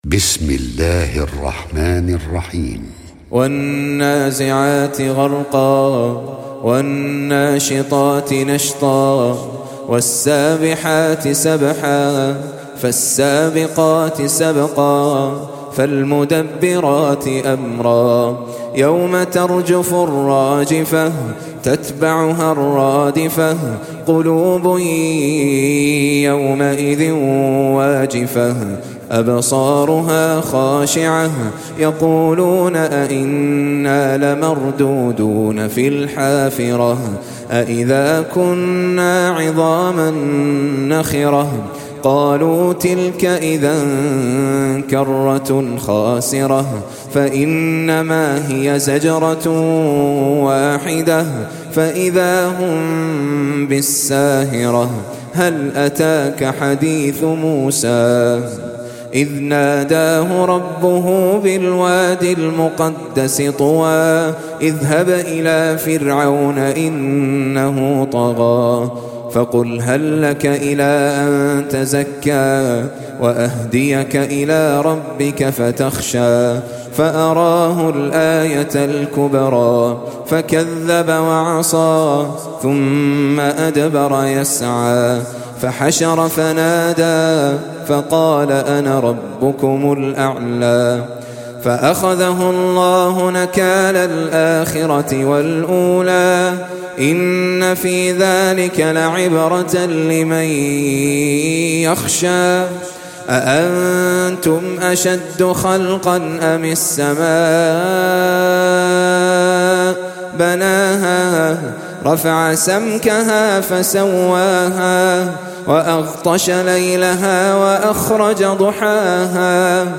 Audio Quran Tajweed Recitation
حفص عن عاصم Hafs for Assem